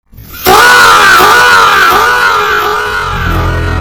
Faaaa Slowed Down Sound Button - Free Download & Play
Sound Effects Soundboard0 views